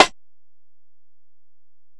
Snare (47).wav